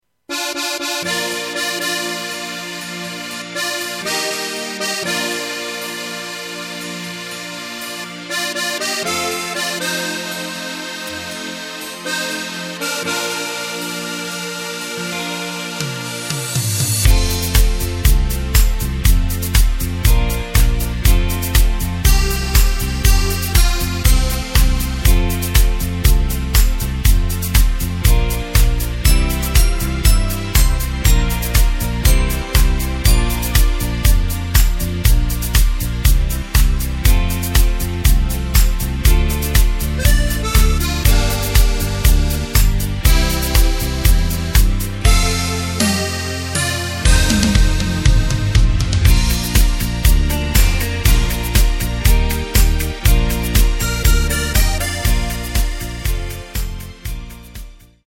Takt:          4/4
Tempo:         120.00
Tonart:            Bb
Schlager aus dem Jahr 2016!
Playback mp3 Demo